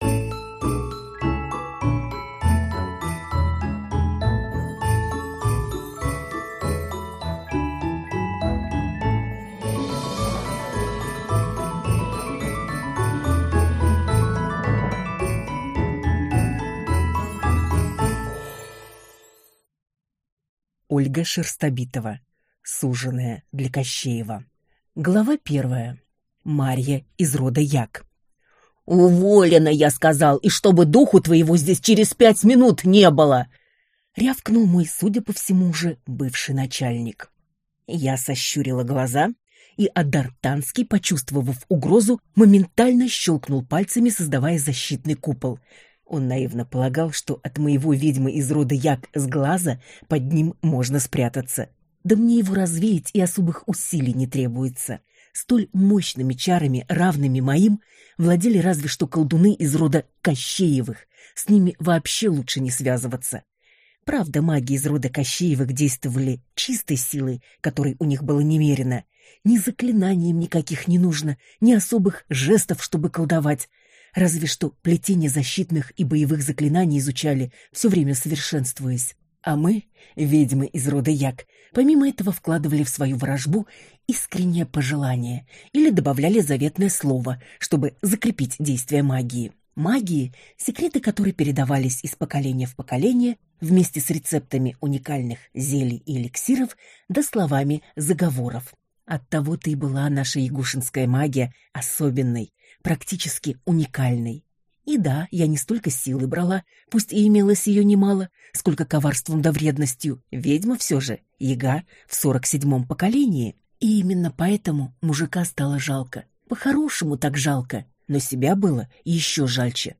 Аудиокнига Суженая для Кощеева | Библиотека аудиокниг